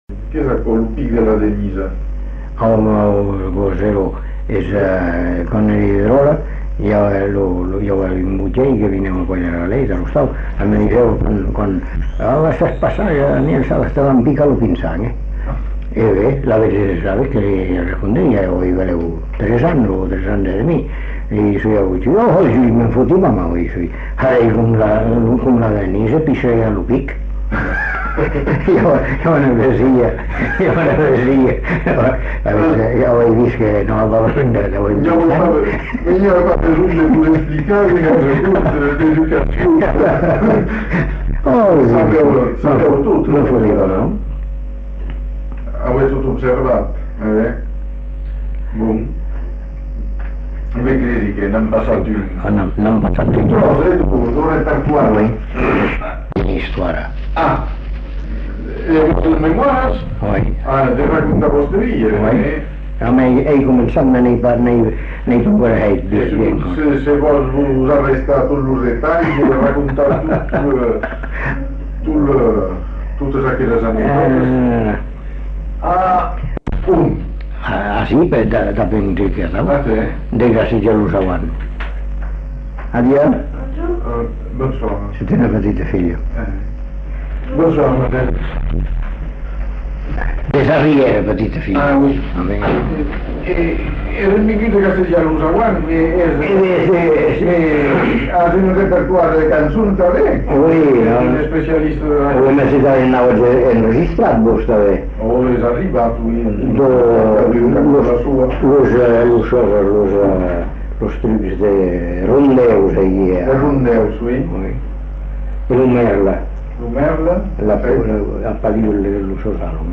Lieu : Bazas
Genre : conte-légende-récit
Type de voix : voix d'homme
Production du son : parlé